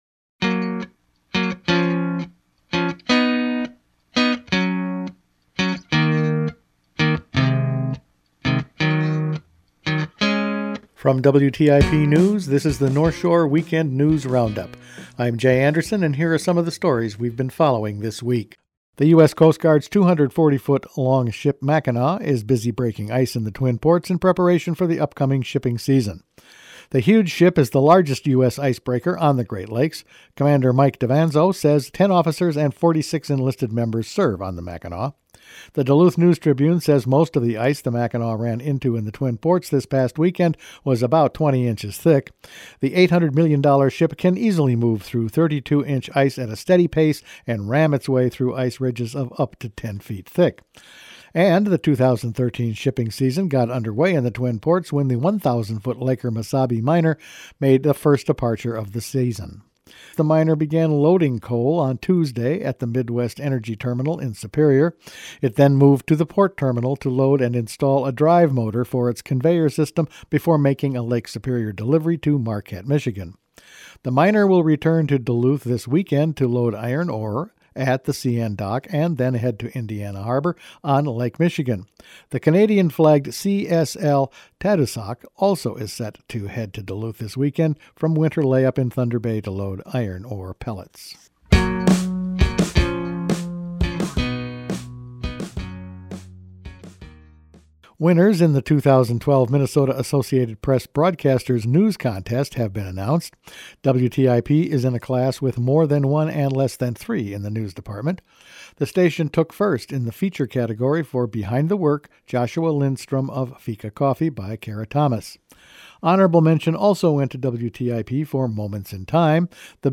Weekend News Roundup for March 23